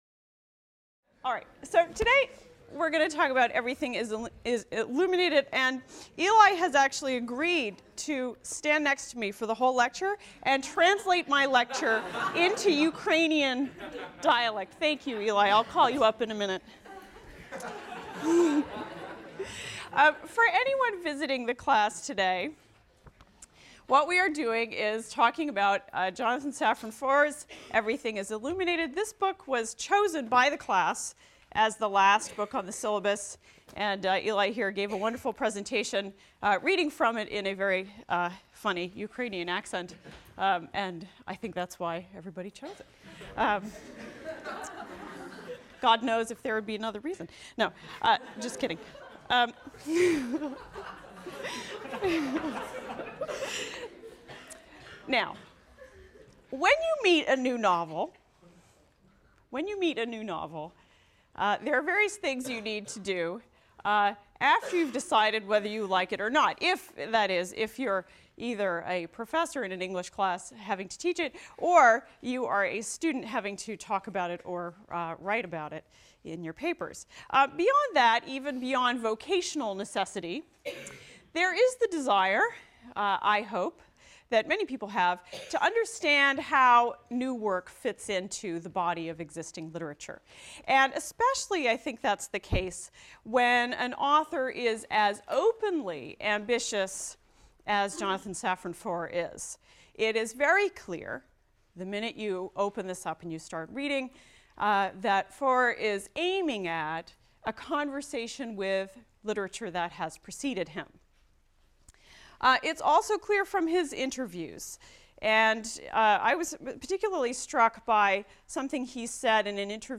ENGL 291 - Lecture 24 - Students’ Choice Novel: Jonathan Safran Foer, Everything is Illuminated | Open Yale Courses